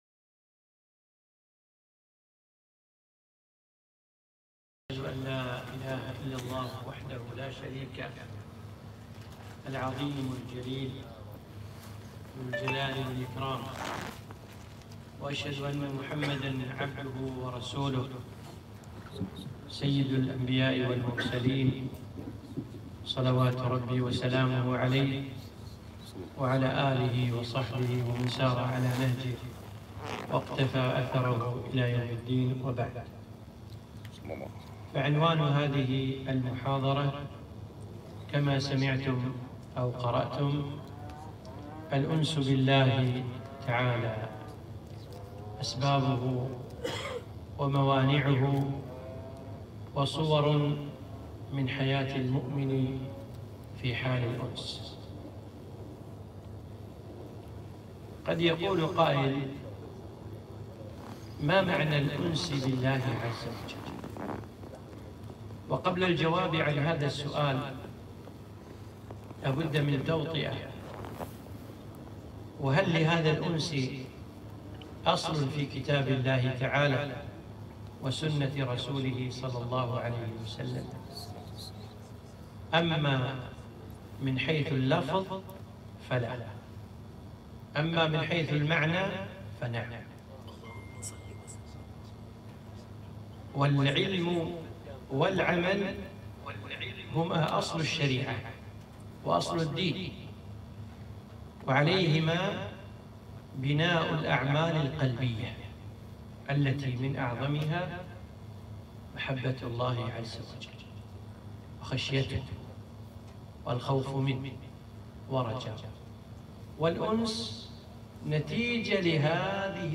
محاضرة -( الأنس بالله تعالى ) أسبابه وموانعه وكيف هي حياة المؤمن معه